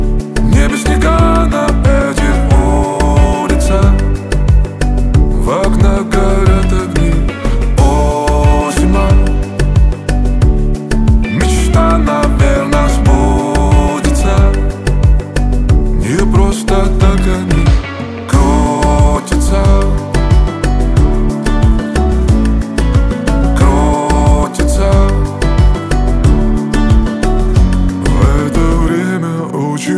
Pop Alternative Indie Pop